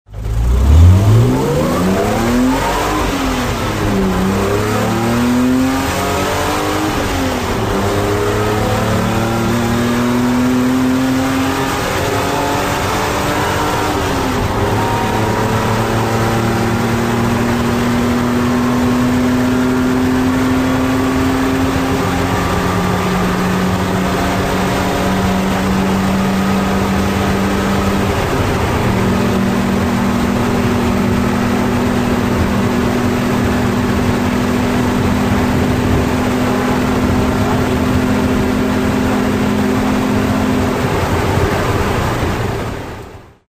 Sons de moteurs bmw - Engine sounds bmw - bruit V8 V10 bmw